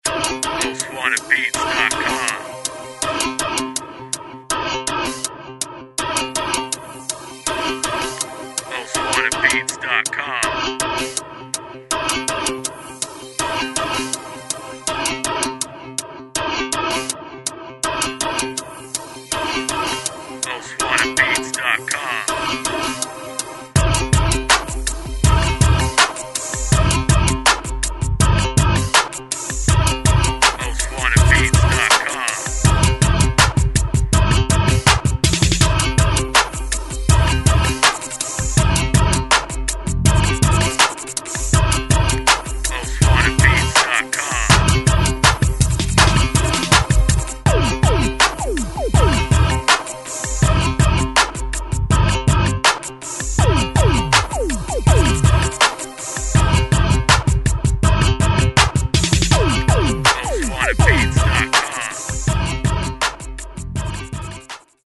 DIRTY SOUTH INSTRUMENTAL